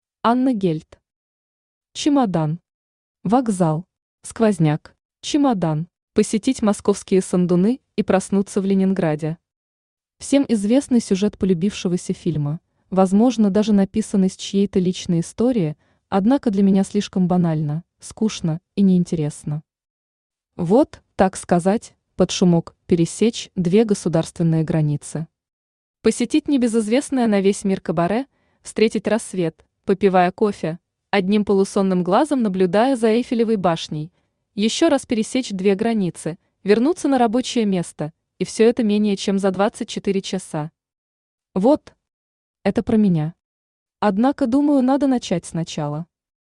Аудиокнига Чемодан. Вокзал. Сквозняк | Библиотека аудиокниг
Сквозняк Автор Анна Гельт Читает аудиокнигу Авточтец ЛитРес.